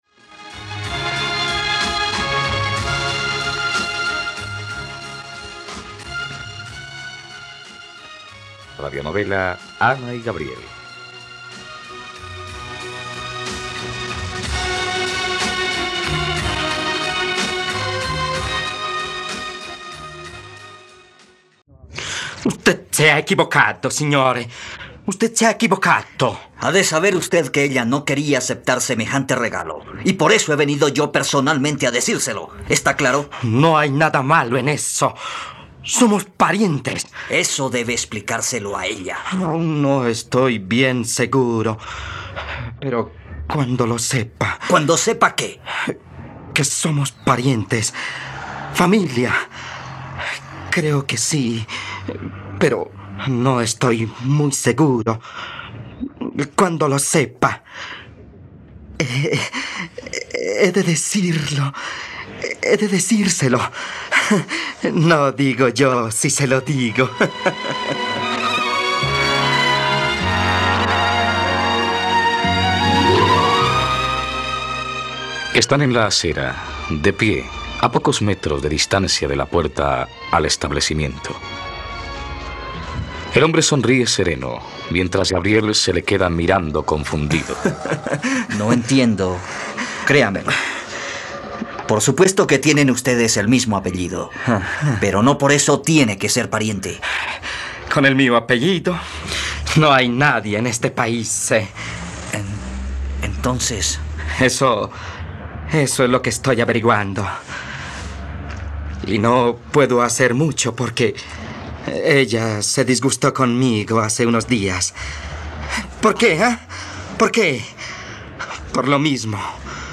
Ana y Gabriel - Radionovela, capítulo 33 | RTVCPlay